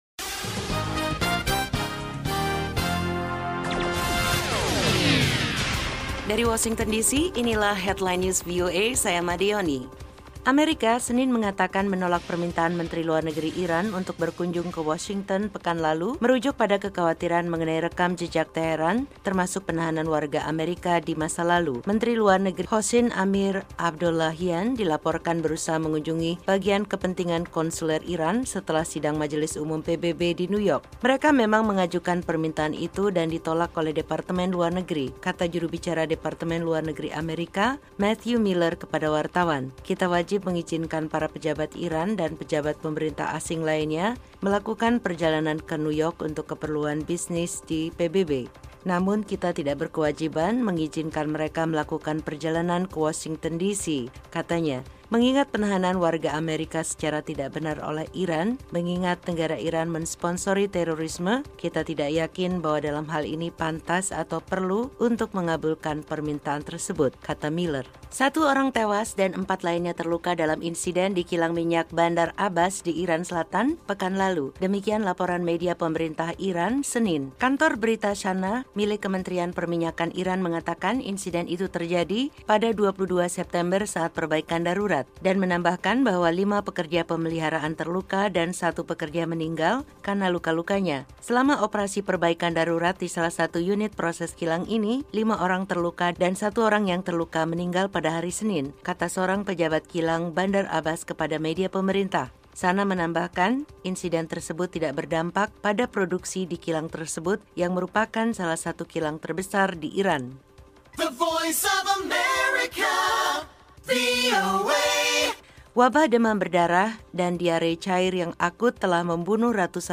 VOA Headline News